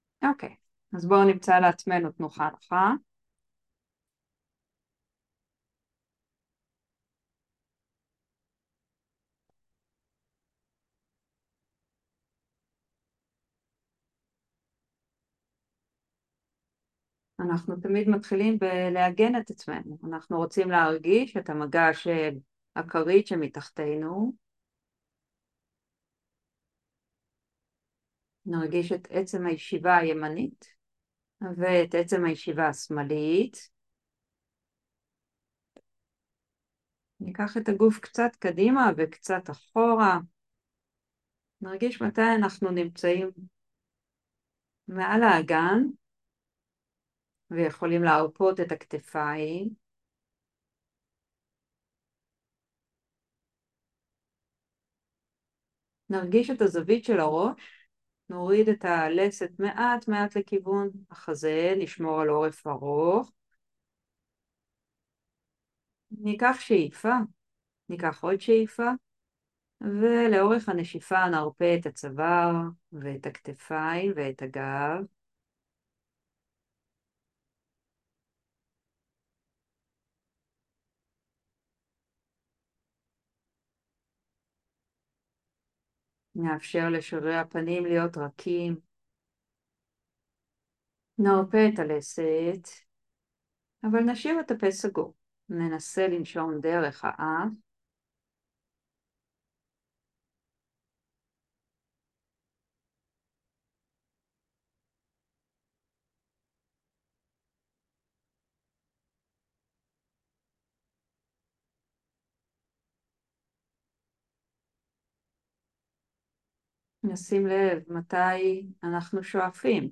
הקלטת מדיטציה מודעות לגבולות הבטן בשאיפה